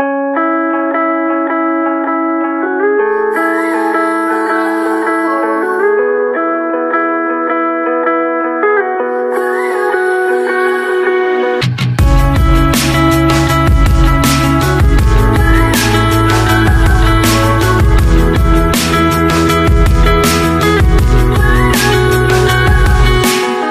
Categoría Clasicos